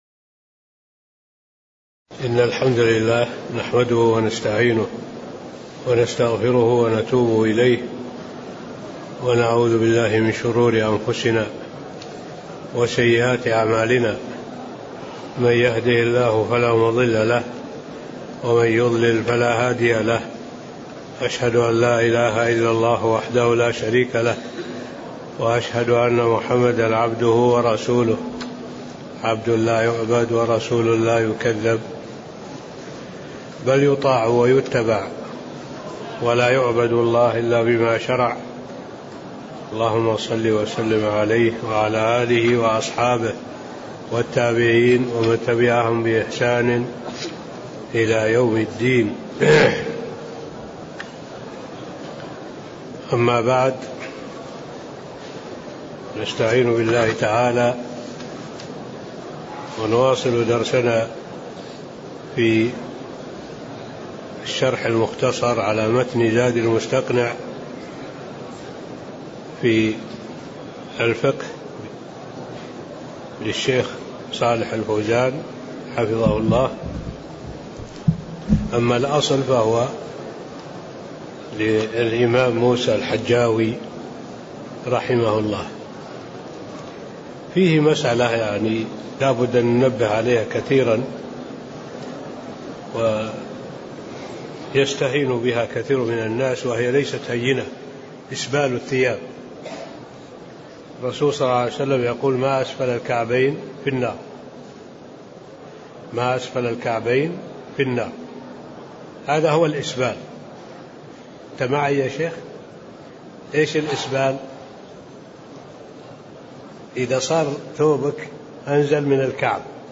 تاريخ النشر ٥ ربيع الثاني ١٤٣٤ هـ المكان: المسجد النبوي الشيخ: معالي الشيخ الدكتور صالح بن عبد الله العبود معالي الشيخ الدكتور صالح بن عبد الله العبود باب نواقض الوضوء (08)